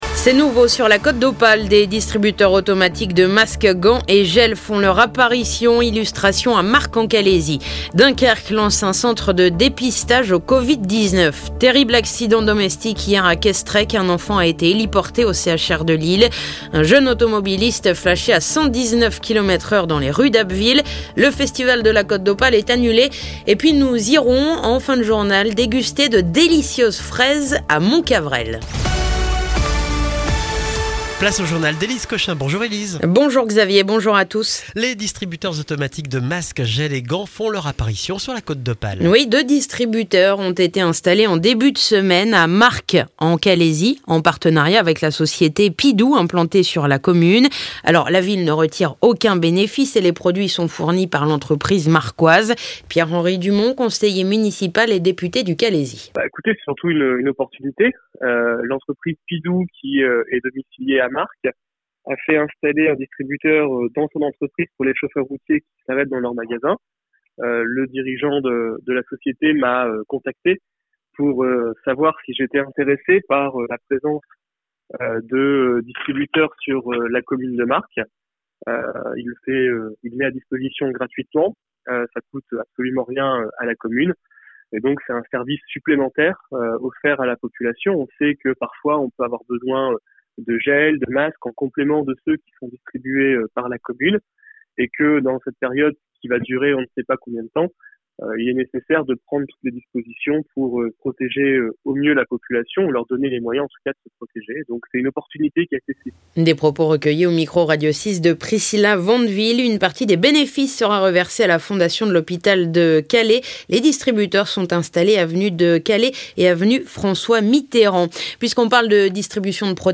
Le journal du jeudi 14 mai